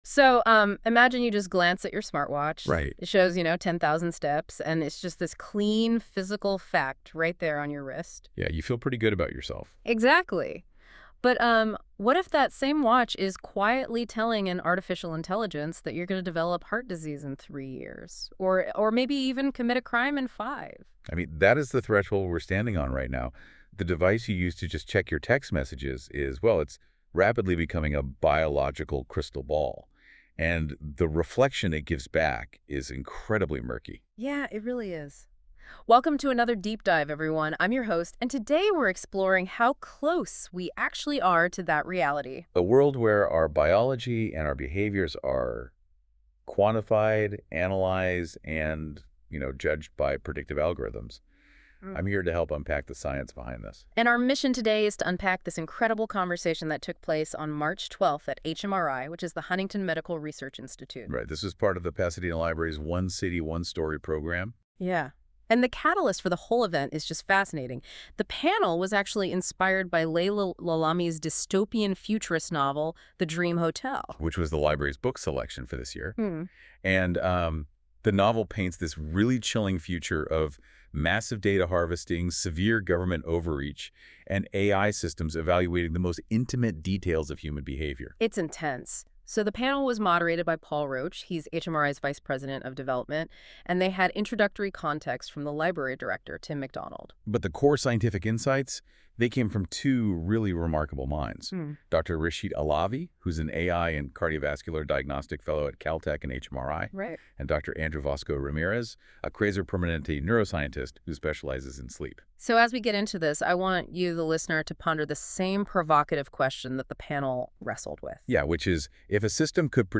A shorter, AI-generated audio summary of this conversation is also available.
AI-Summary-DreamsDataDestiny.m4a